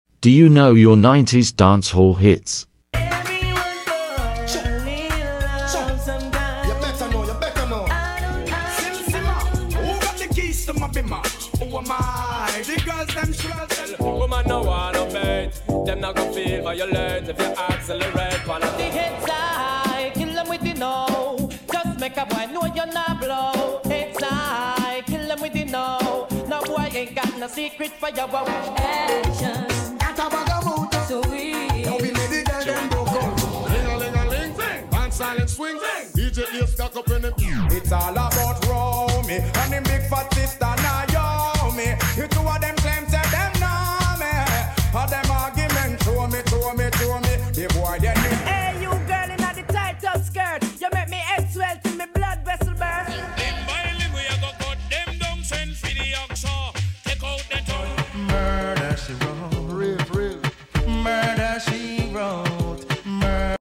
dancehall hits